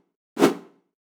slash_A.wav